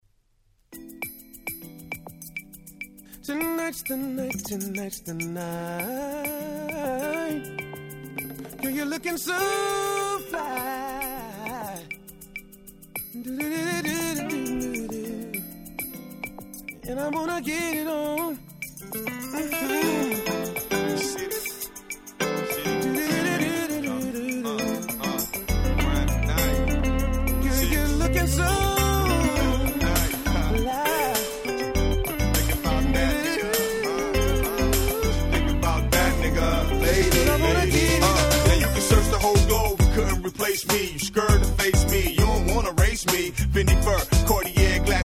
大変気持ちの良い夏向けな1枚です！